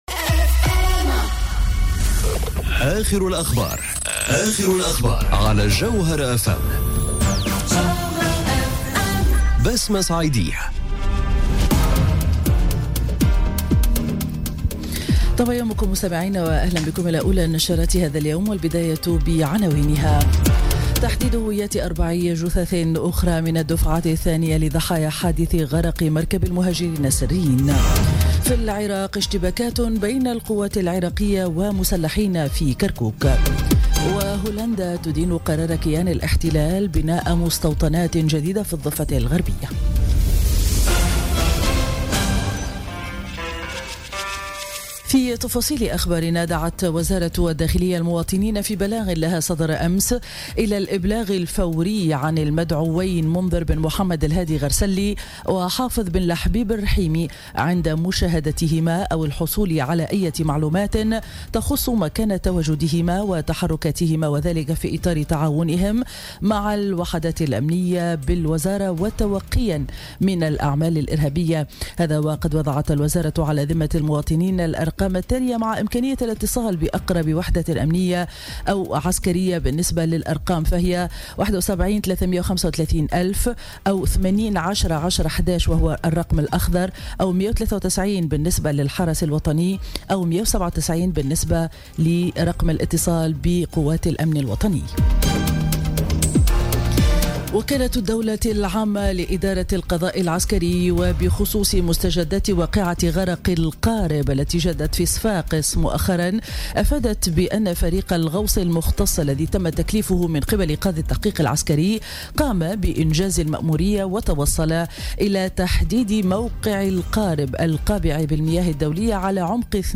نشرة أخبار السابعة صباحا ليوم الجمعة 20 أكتوبر 2017